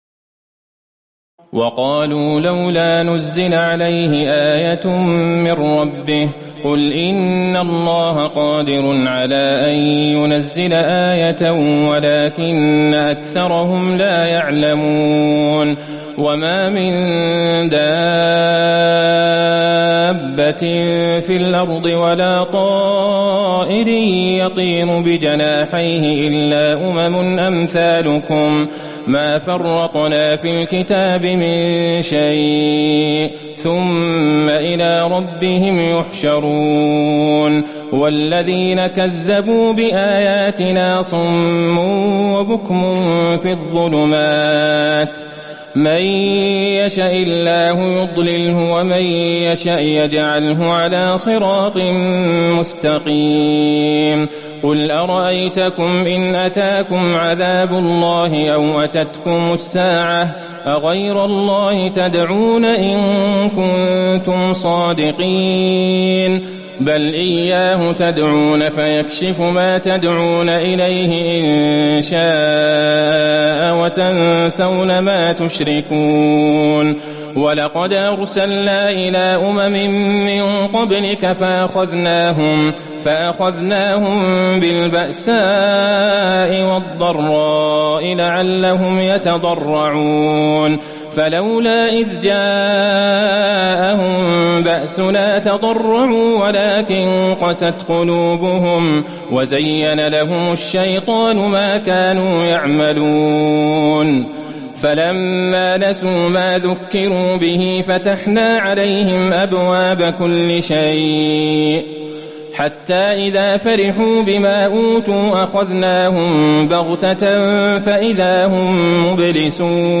تراويح الليلة السابعة رمضان 1438هـ من سورة الأنعام (37-111) Taraweeh 7 st night Ramadan 1438H from Surah Al-An’aam > تراويح الحرم النبوي عام 1438 🕌 > التراويح - تلاوات الحرمين